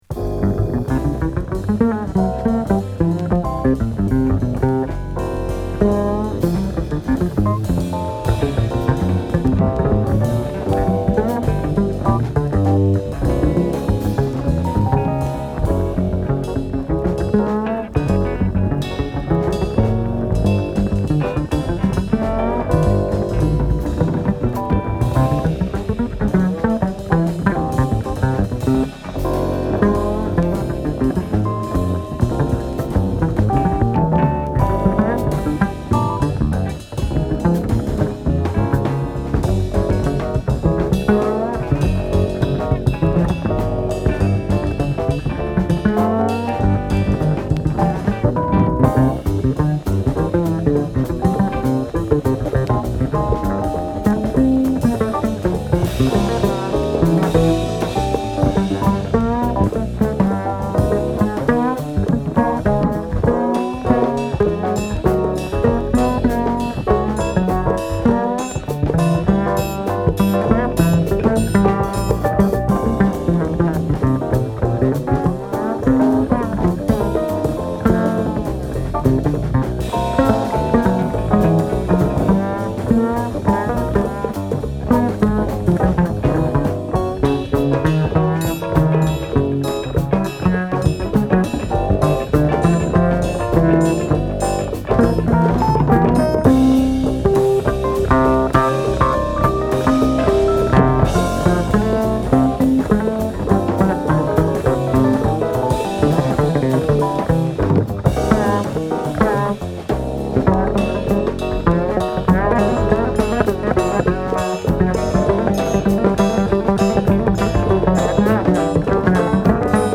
クールでスマイリーな1枚です。